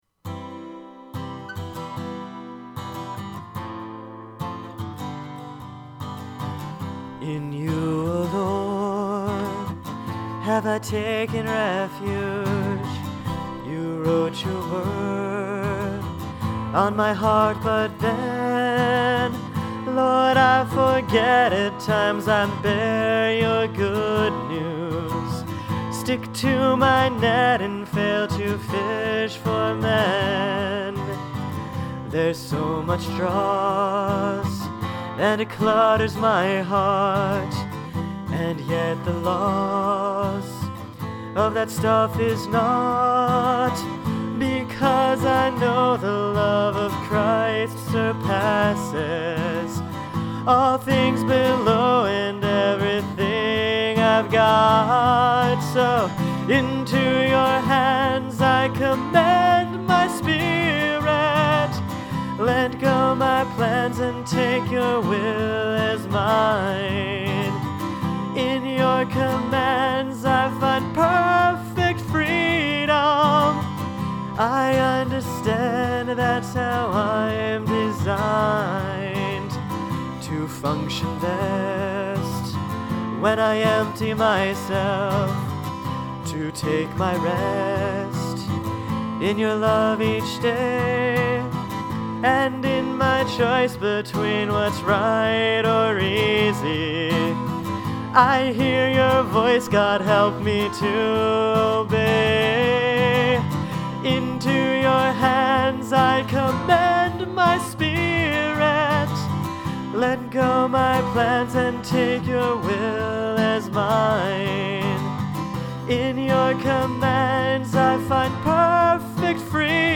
For each song, I gave myself no more than two hours to write and one hour to record it. These are by no means polished songs; they are the responses of my heart to Christ crucified.